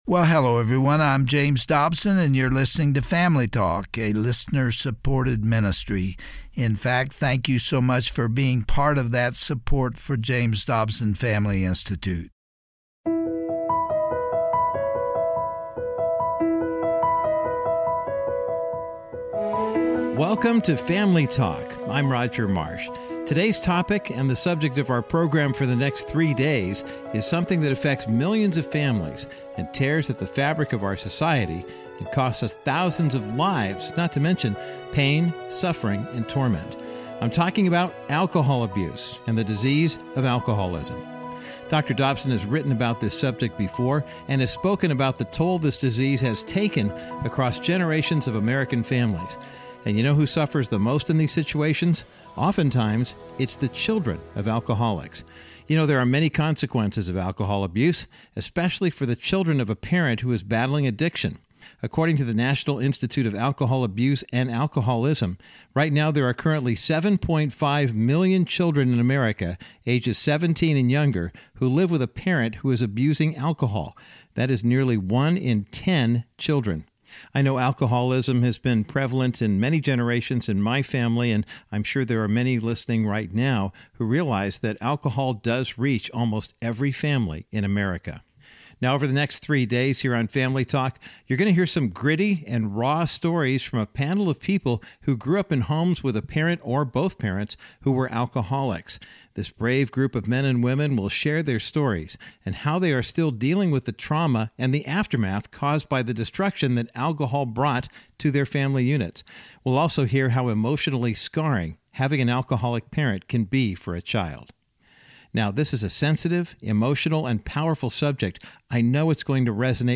On today’s classic edition of Family Talk, Dr. James Dobson interviews a panel of guests to discuss the traumatic and far-reaching effects that alcoholism inflicted on them as children. Discover the hope that can be found in Christ and the safety of support groups as the guests share the pain of their past.